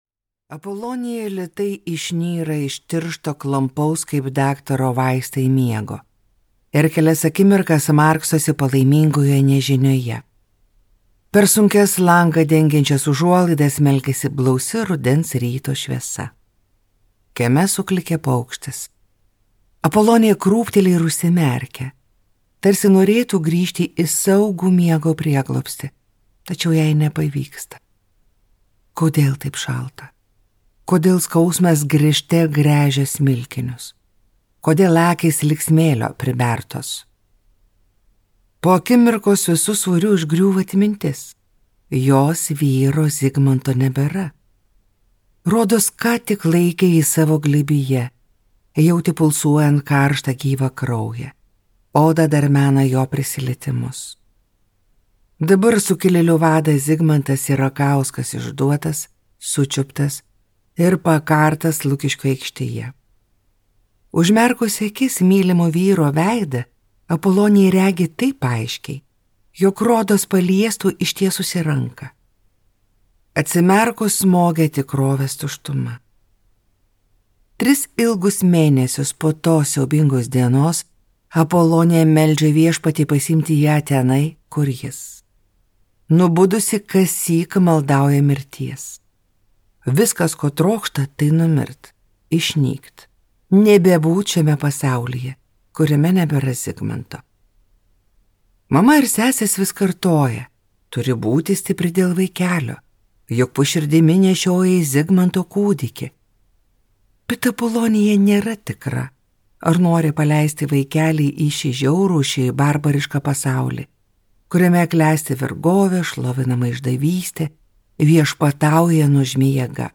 Apolonija | Audioknygos | baltos lankos